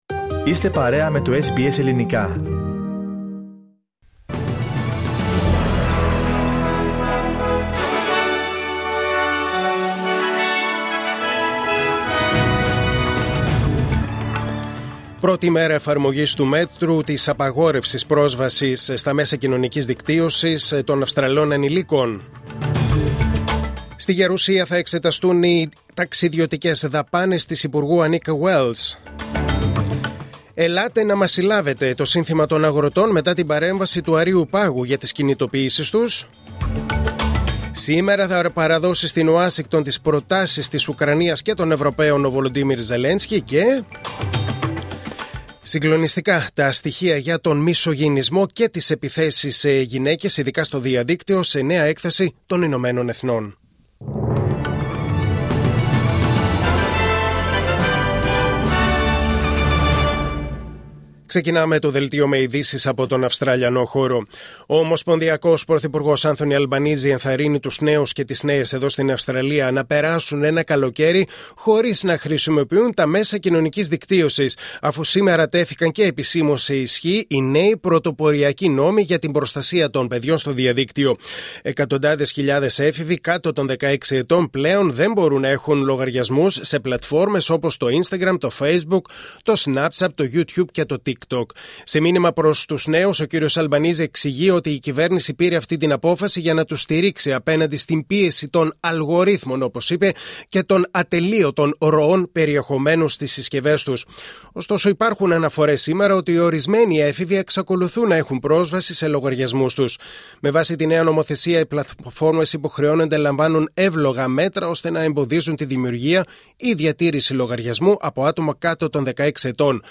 Δελτίο Ειδήσεων Τετάρτη 10 Δεκεμβρίου 2025